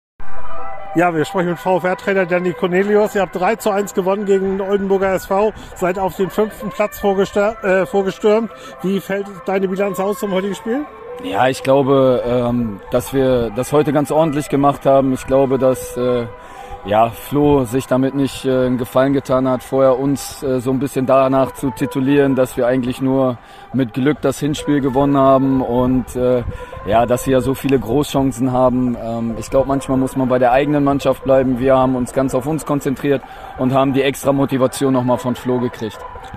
Stimme zum Spiel